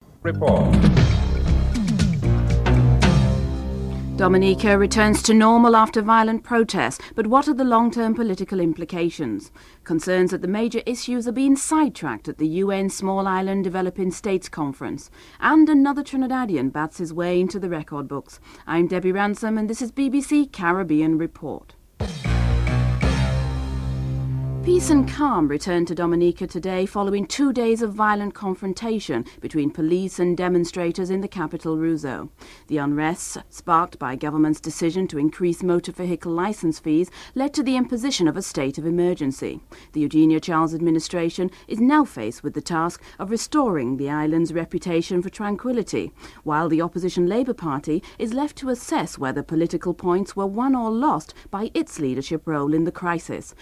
9. Wrap up & theme music (14:33-14:48)